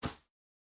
Shoe Step Grass Hard C.wav